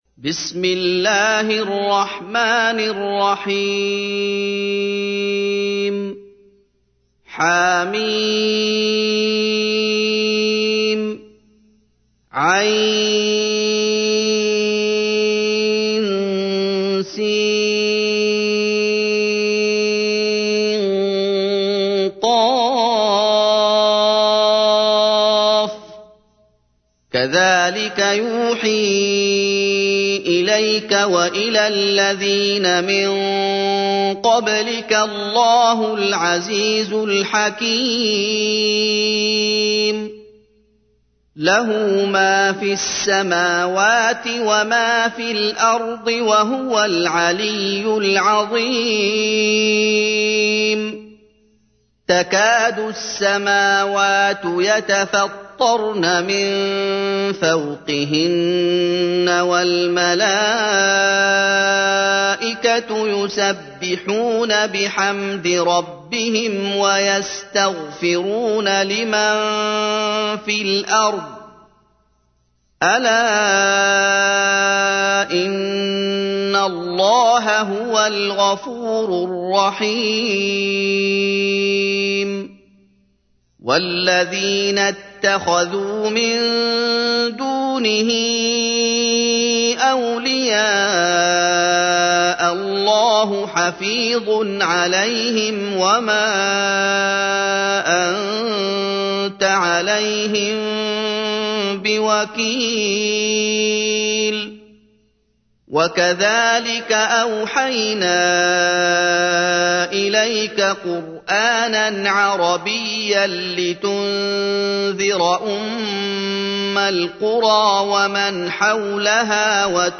تحميل : 42. سورة الشورى / القارئ محمد أيوب / القرآن الكريم / موقع يا حسين